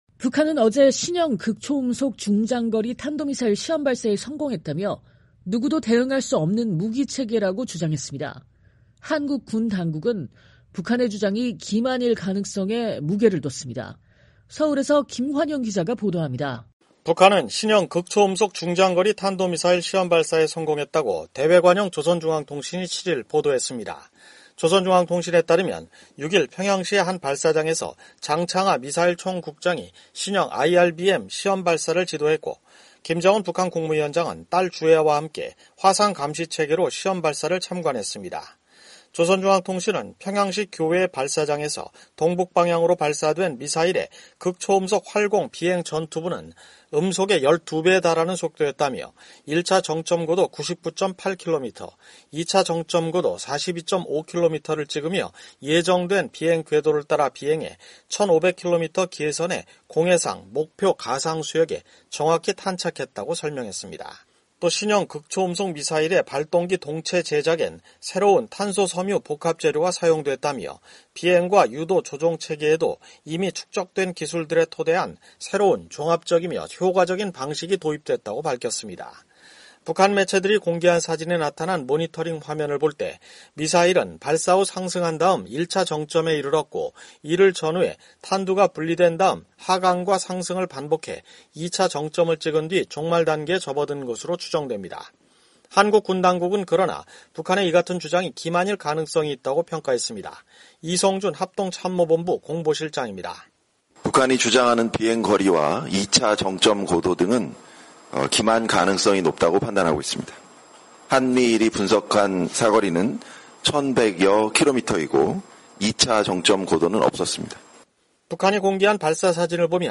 북한은 어제(6일) 신형 극초음속 중장거리 탄도미사일 시험발사에 성공했다며 누구도 대응할 수 없는 무기체계라고 주장했습니다. 한국 군 당국은 북한의 주장이 기만일 가능성에 무게를 뒀습니다. 서울에서